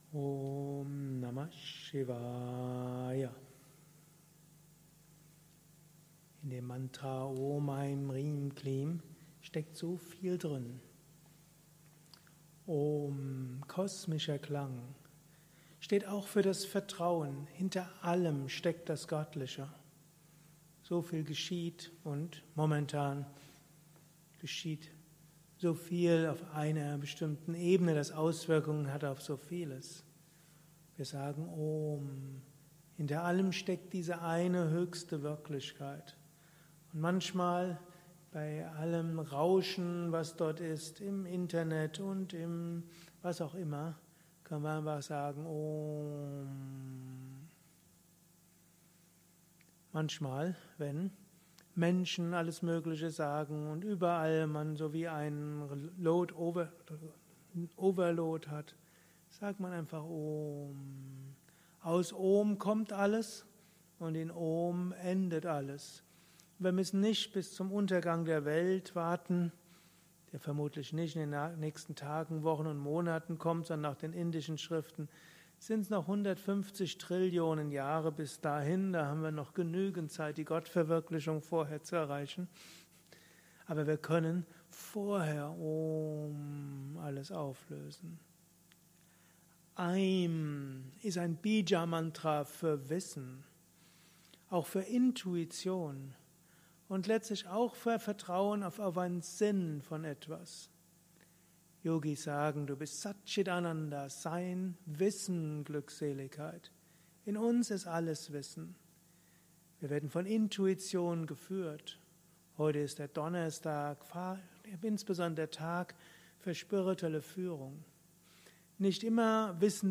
Höre Erläuterungen zum Thema “Über das Mantra Om” als Inspiration des Tages. Dies ist ein kurzer Vortrag
eine Aufnahme während eines Satsangs gehalten nach einer Meditation im Yoga Vidya Ashram Bad Meinberg.